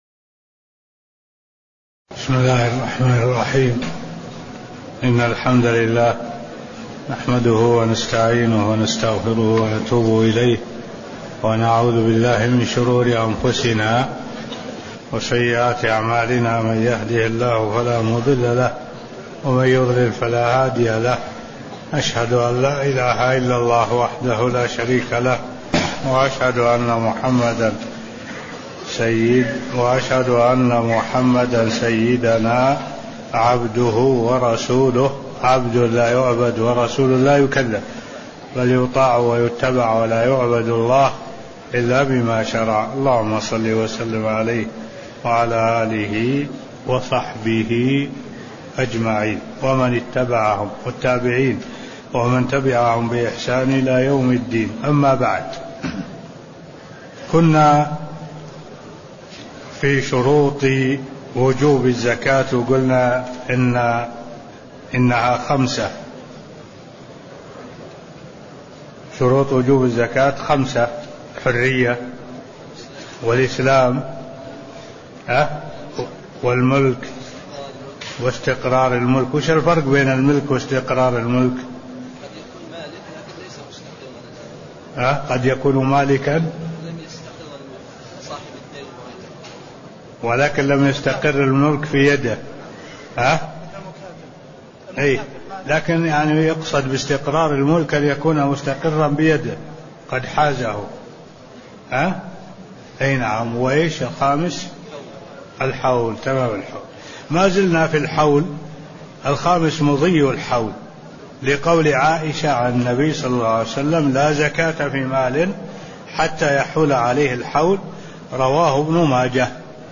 تاريخ النشر ١ محرم ١٤٢٧ هـ المكان: المسجد النبوي الشيخ: معالي الشيخ الدكتور صالح بن عبد الله العبود معالي الشيخ الدكتور صالح بن عبد الله العبود شروط وجوب الزكاة (002)